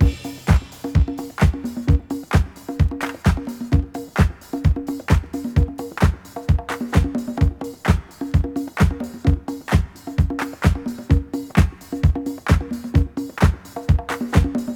• 130 Bpm Modern Breakbeat C Key.wav
Free drum loop sample - kick tuned to the C note. Loudest frequency: 564Hz
130-bpm-modern-breakbeat-c-key-mtg.wav